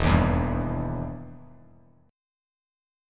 EmM7 chord